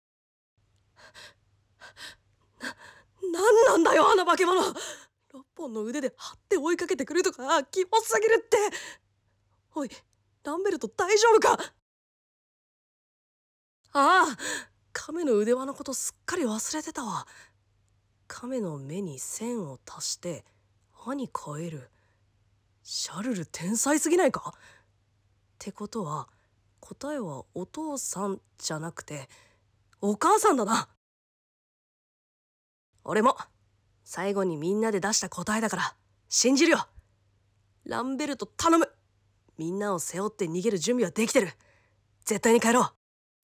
声劇②🌀 nanaRepeat